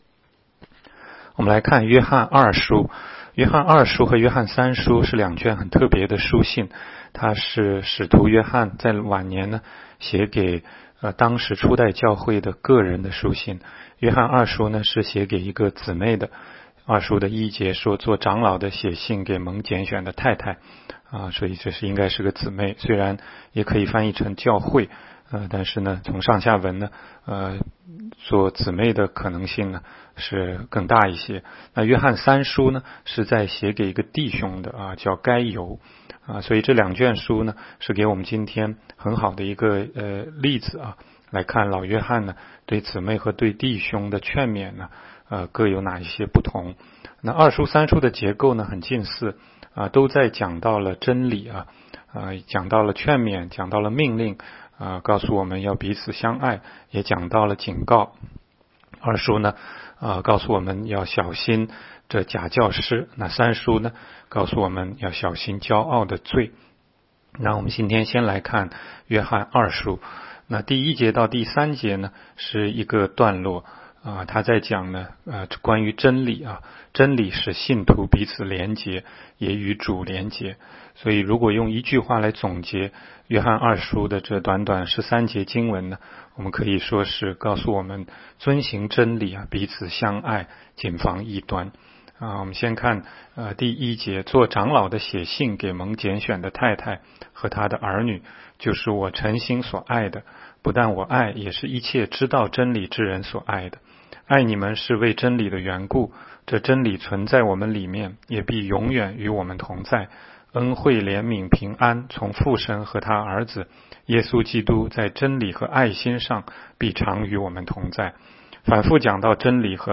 16街讲道录音 - 每日读经-《约翰二书》